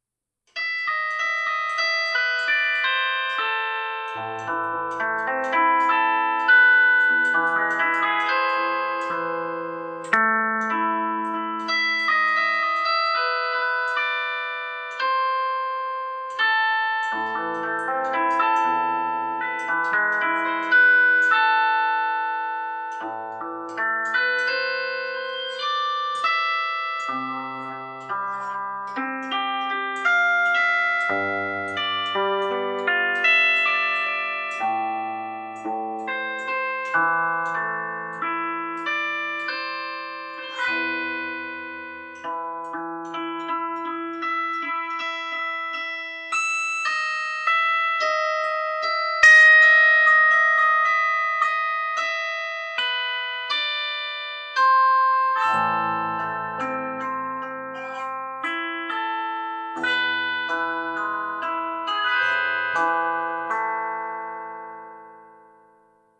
Demo_Mogees_Beethoven.mp3